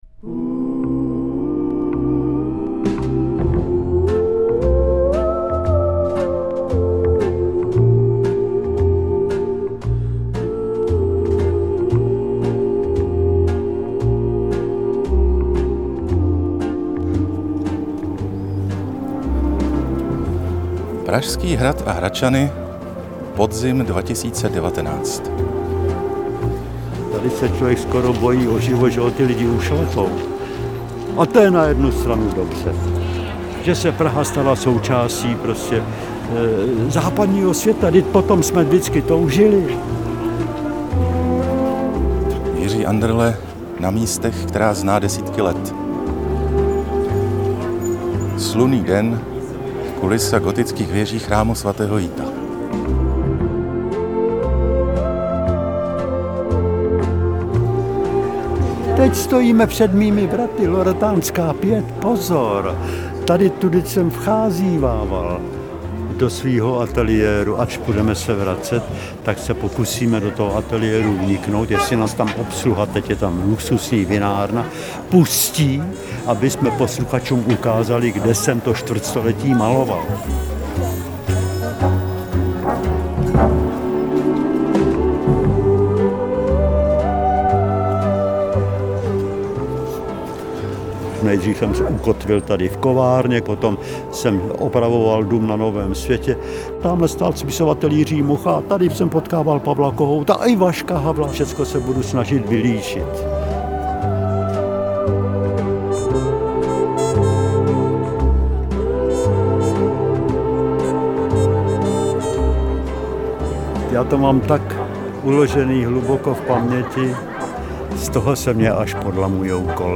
AudioKniha ke stažení, 18 x mp3, délka 1 hod. 6 min., velikost 66,3 MB, česky